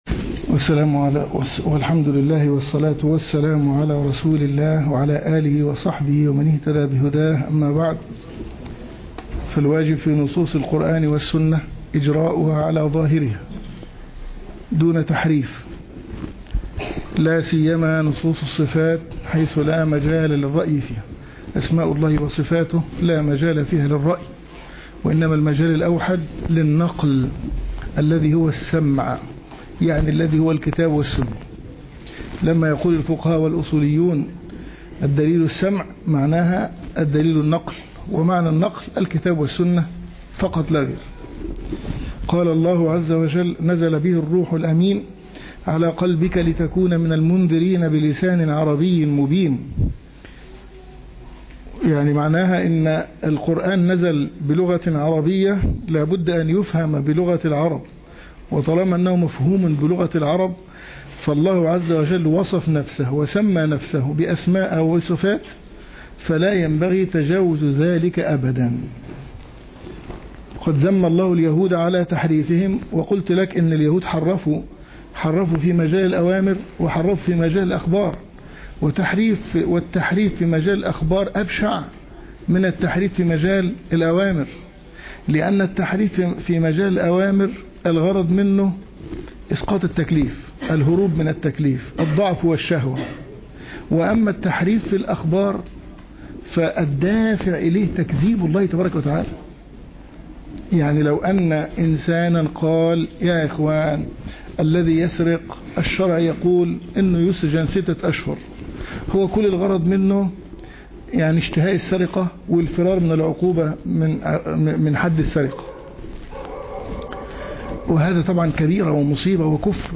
شرح القواعد المثلى في صفات الله وأسمائه الحسنى الدرس 8